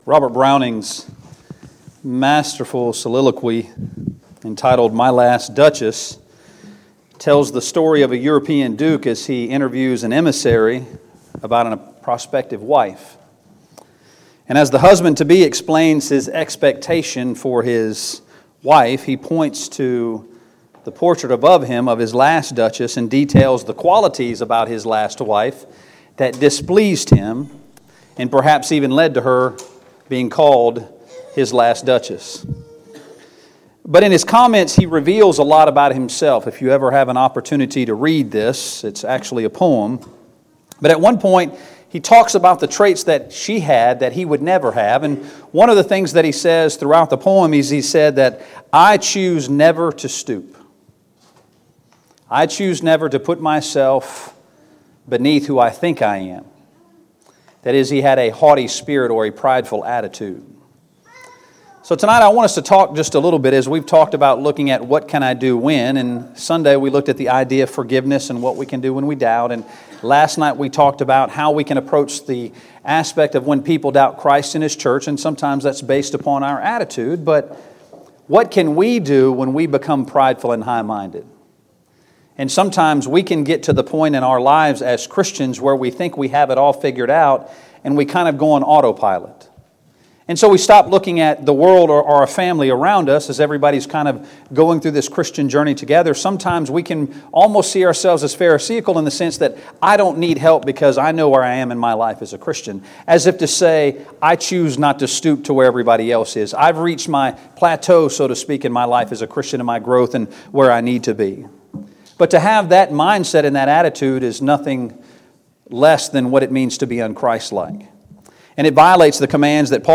Series: 2019 Spring Gospel Meeting Service Type: Gospel Meeting Preacher